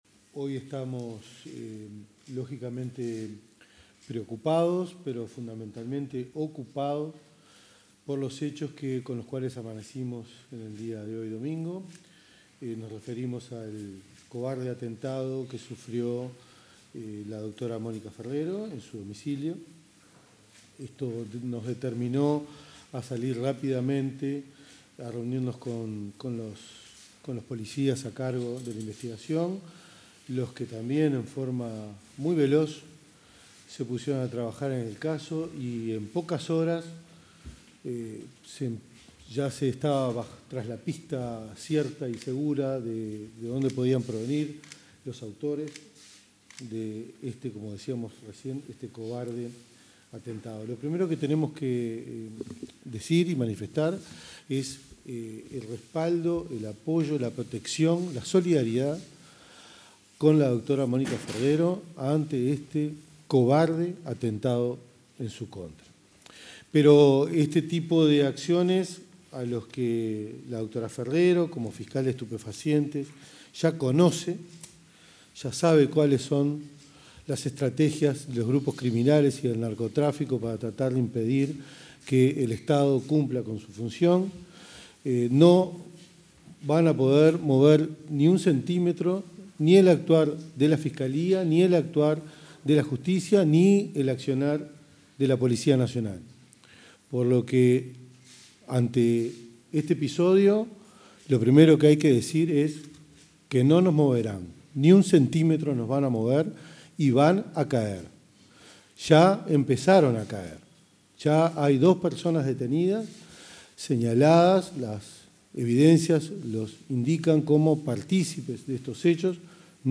Conferencia de prensa de autoridades del Ministerio del Interior
El ministro del Interior, Carlos Negro, y el director de la Policía Nacional, José Azambuya, efectuaron declaraciones a la prensa con motivo del
Estuvieron acompañados por otras autoridades policiales.